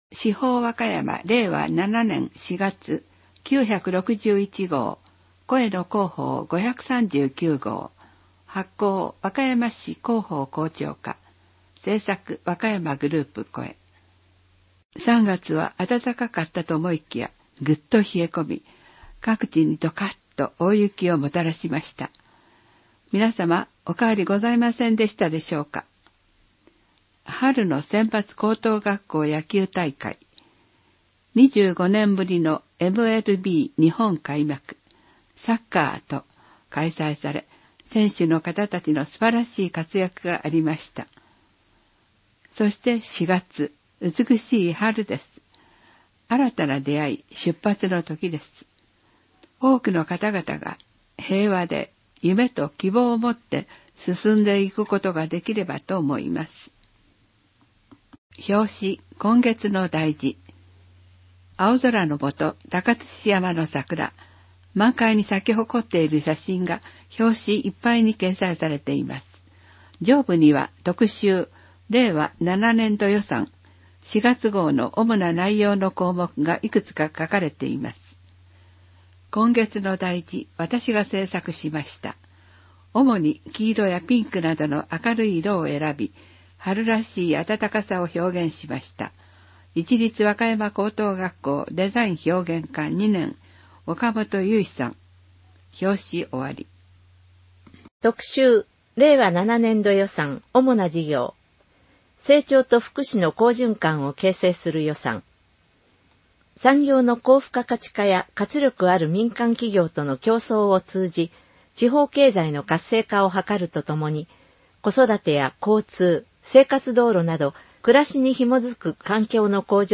市報わかやま 令和7年4月号（声の市報）